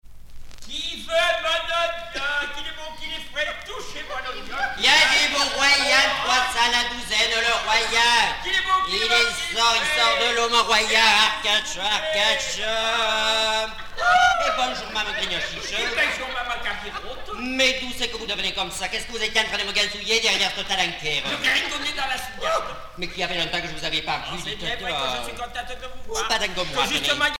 Genre sketch